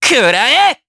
Neraxis-Vox_Attack4_jp.wav